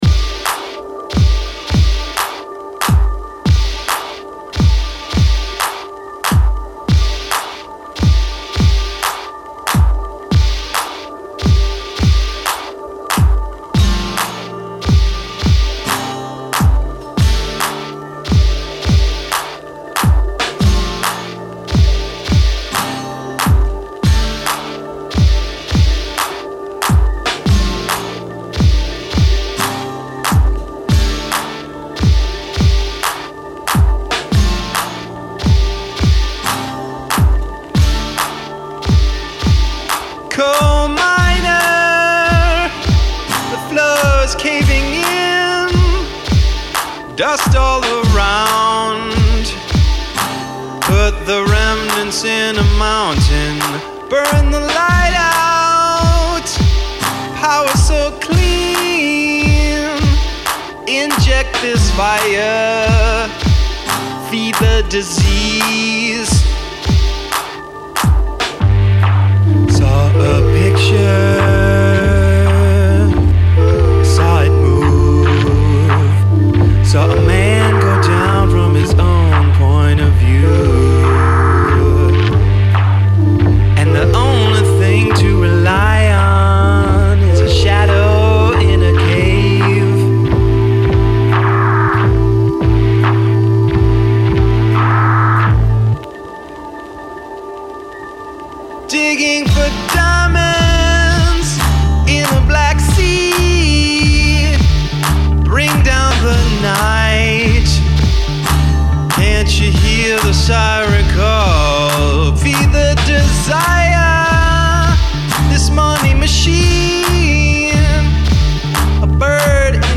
through a poetic and cinematic musical approach